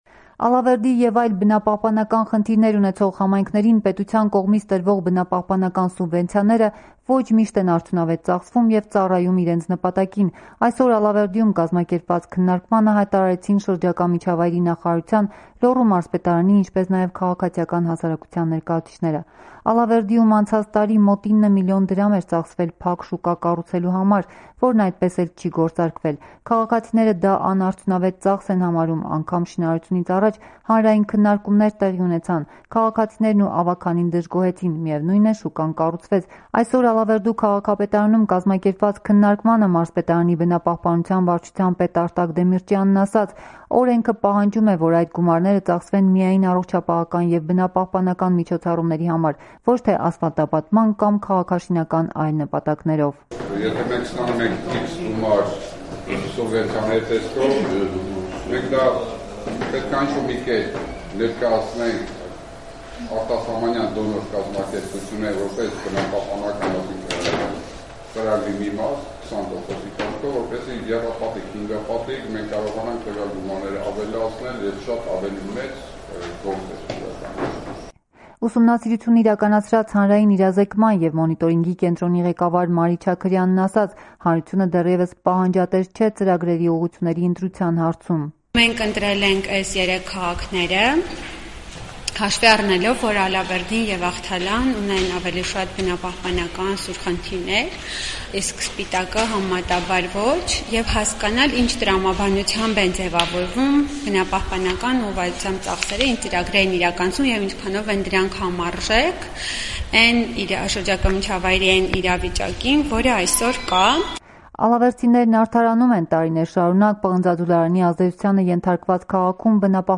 Բնապահպանական սուբվենցիաները ոչ միշտ են արդյունավետ ծախսվում. Քննարկում Ալավերդիում